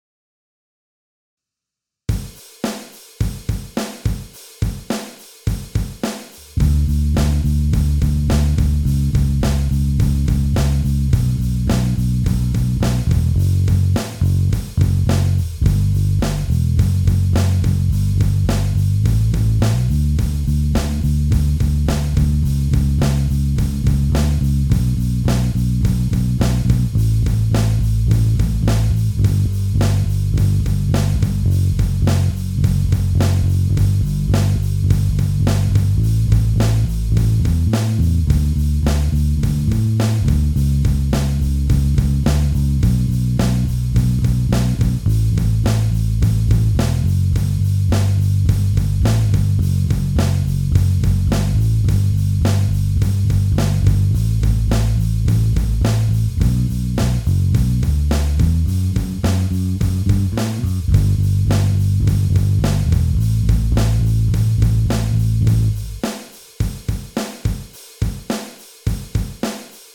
バランスボールに座って弾くと結構リズムがよれるw